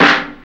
OLD SCHOOL.wav